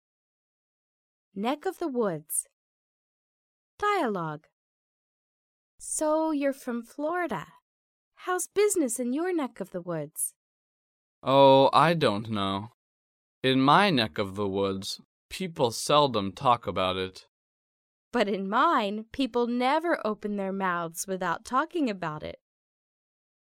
第一， 迷你对话